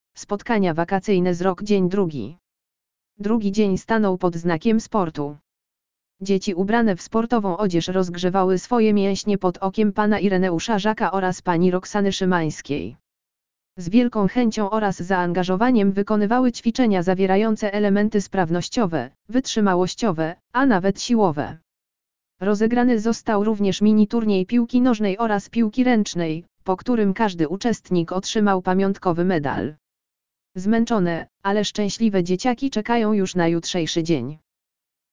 lektor_audio_spotkania_wakacyjne_z_rok_dzien_drugi.mp3